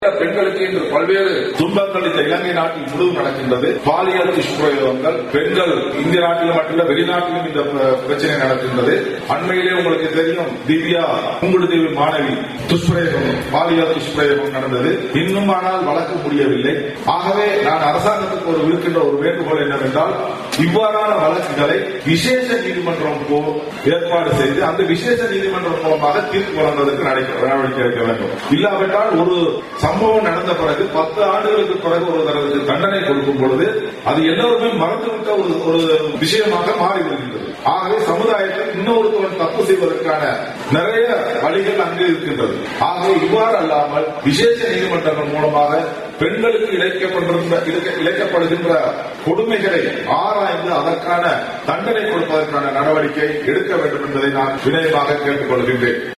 மஸ்கெலியாவில் இடம்பெற்ற நிகழ்வொன்றில் வைத்து அவர் இதனை தெரிவித்தார்.